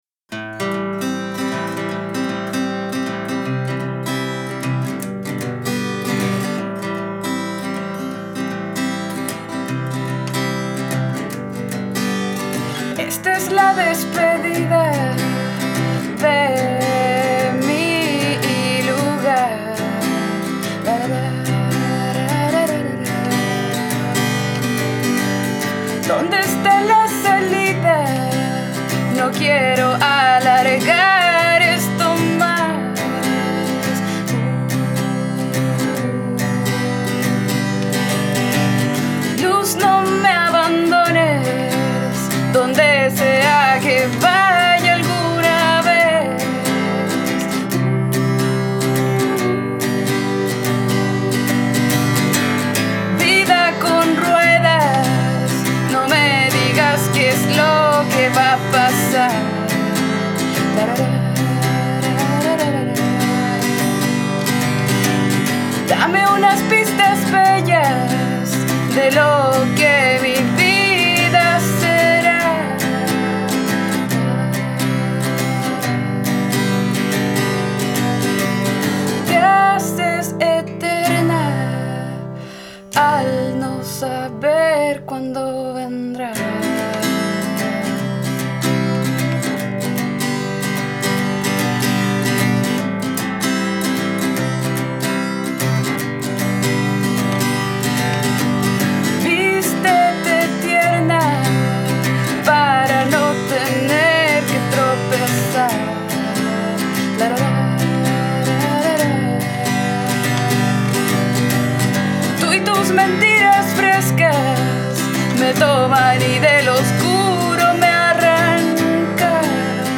voz y guitarra
arreglos y teclados